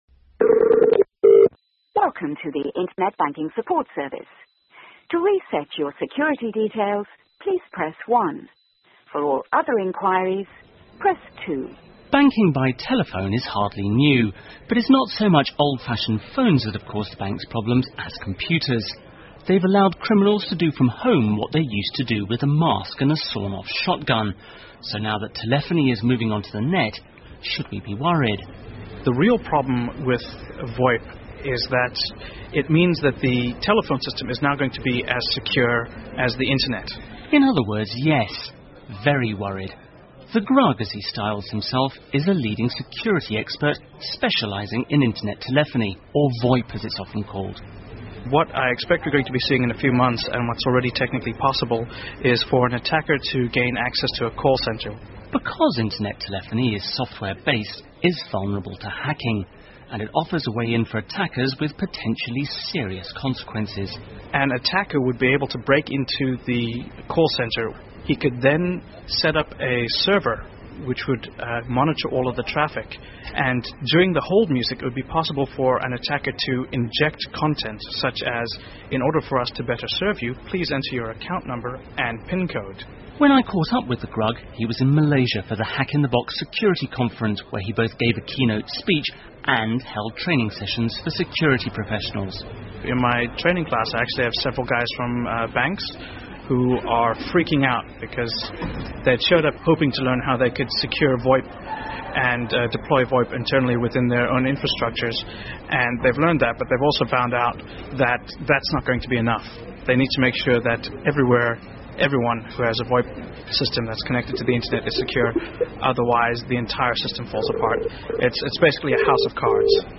英国新闻听力 电话银行的危险性 听力文件下载—在线英语听力室